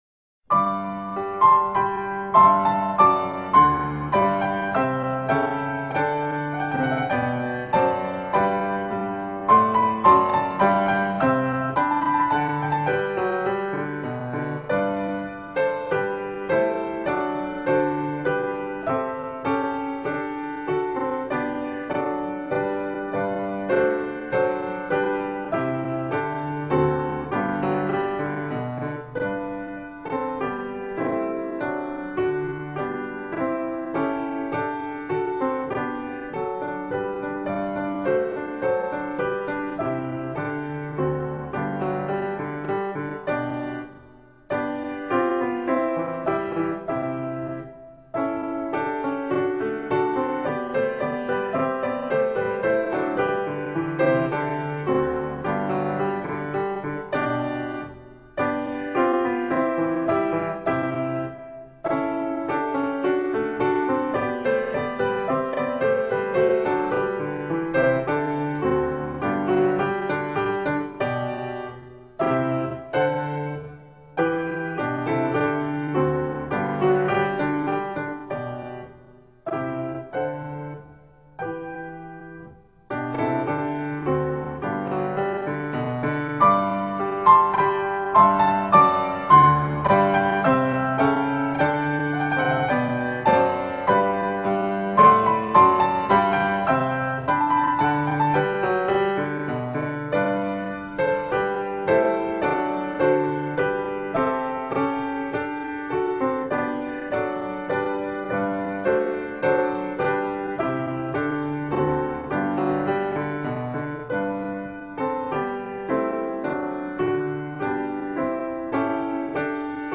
base pianoforte.
Per-La-Gloria-Dadorarvi-base.wma